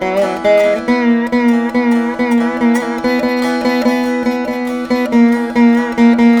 140  VEENA.wav